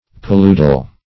Paludal \Pa*lu"dal\, a. [L. palus, -udis, a marsh.]